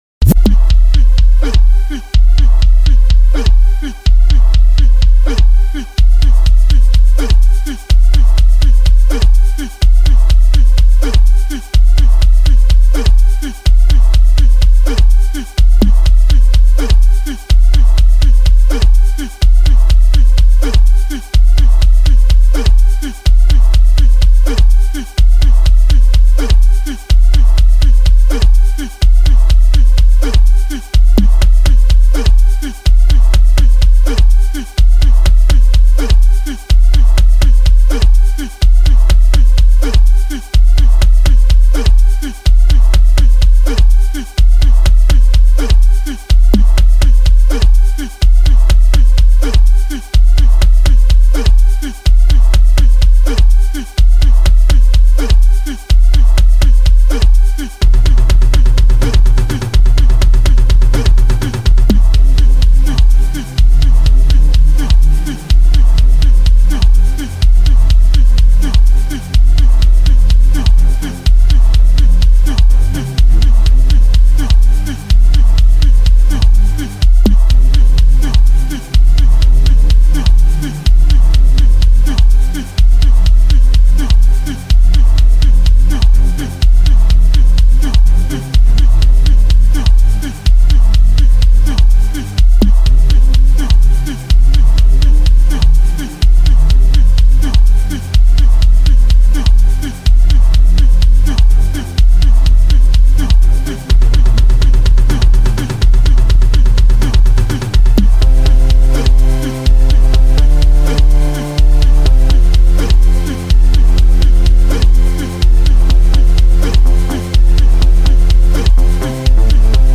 Genre : Gqom